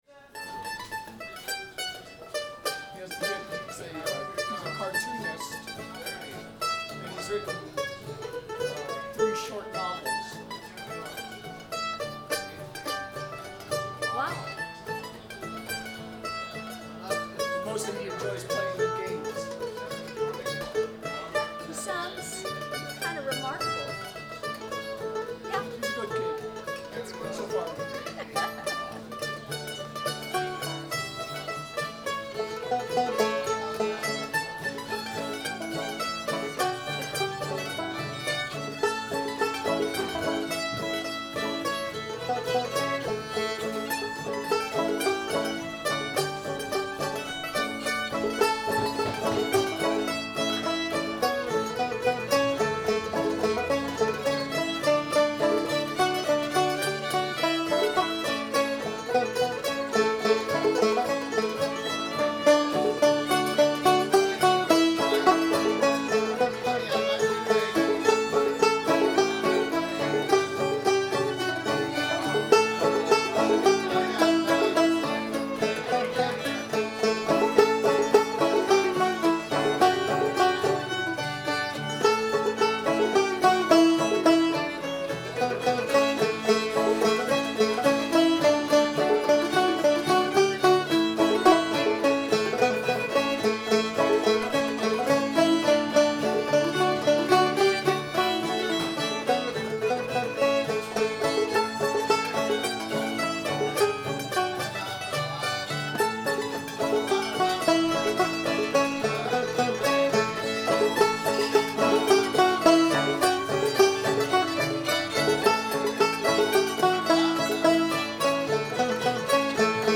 texas [A]